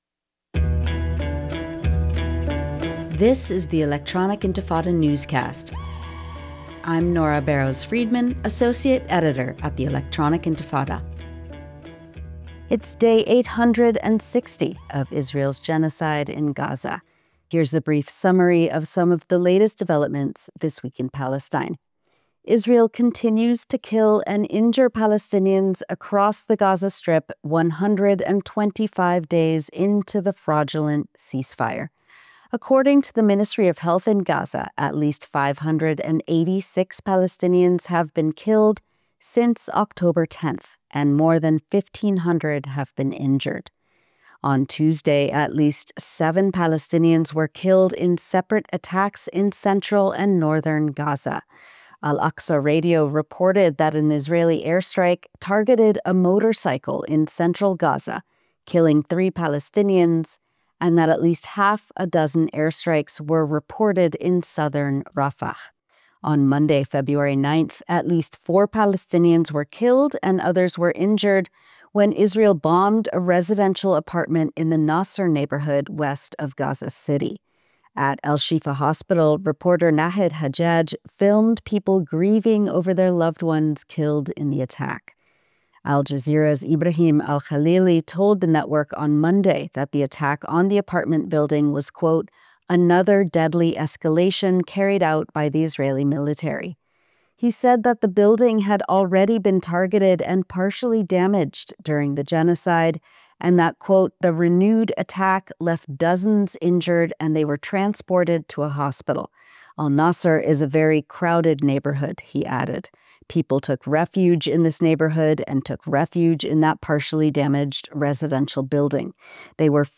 Electronic Intifada Newscast February 12th, 2026